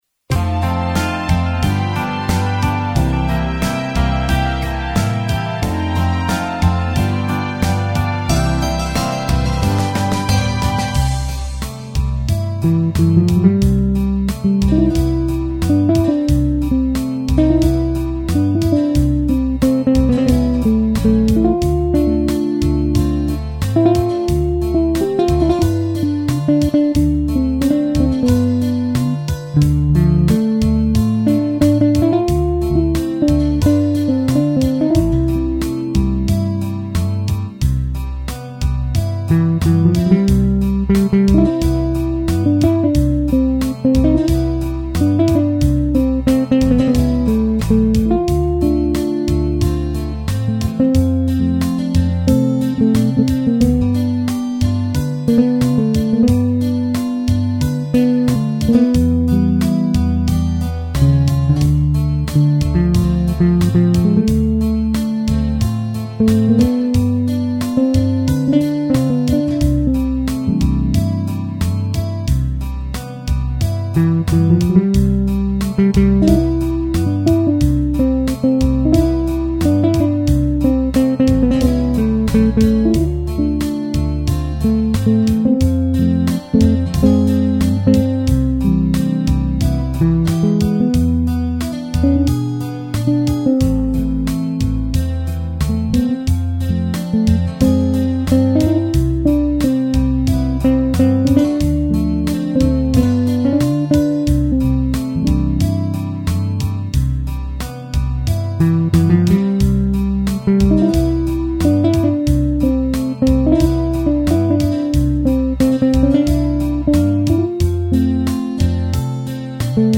instrumental
teclado